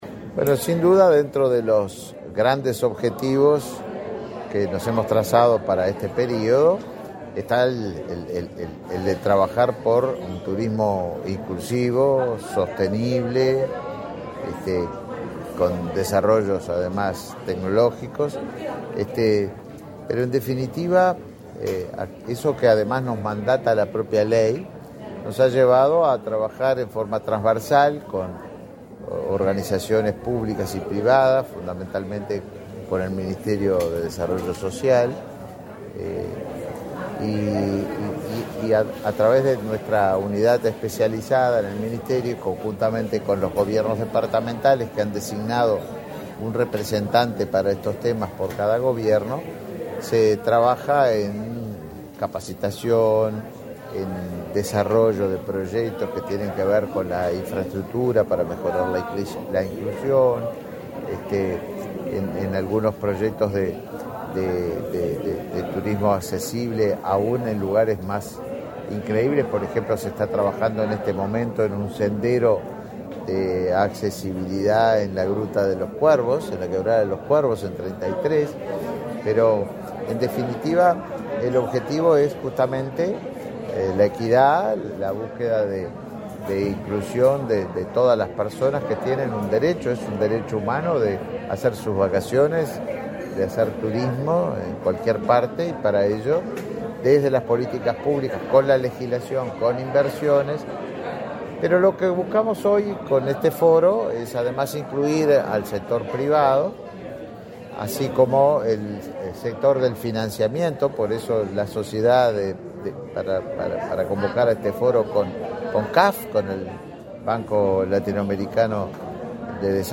Declaraciones a la prensa del ministro de Turismo, Tabaré Viera